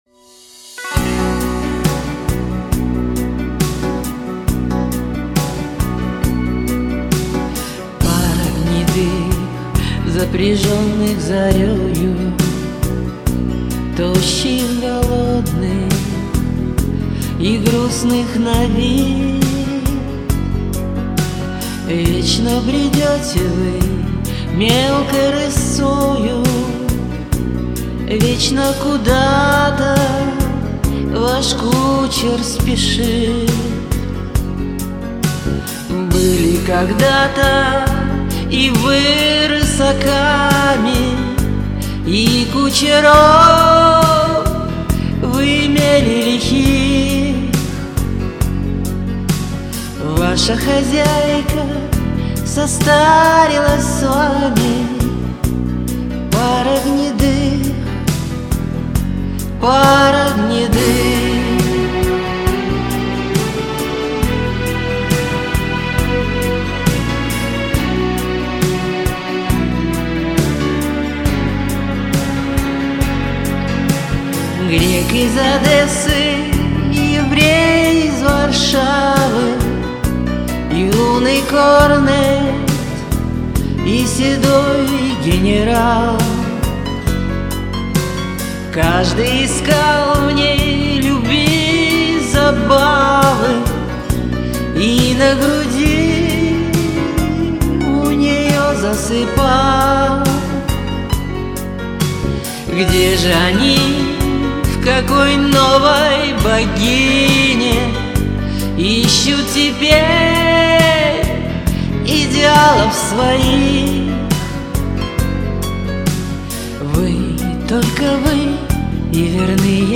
«Русский классический романс»
Комментарий инициатора: Комментарий соперника: Неклассическое исполнение, виновата..... но минус диктует...